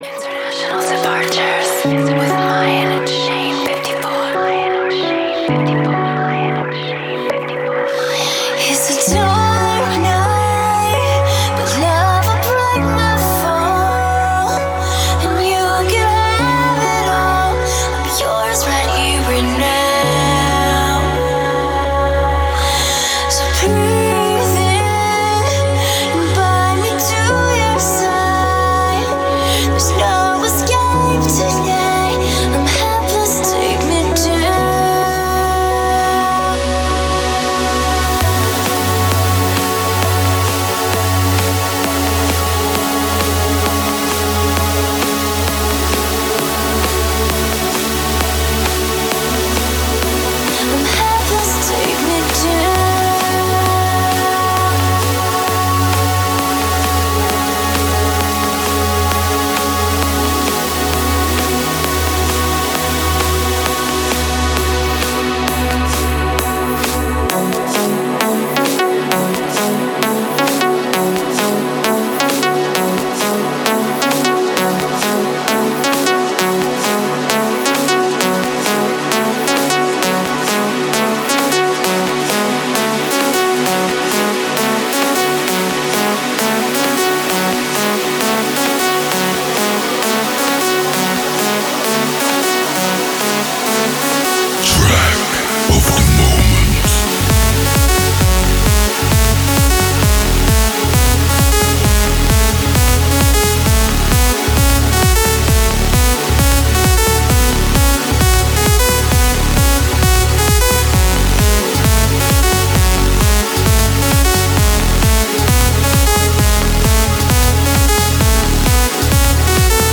Женский_вокал
ZHenskiq_vokal.mp3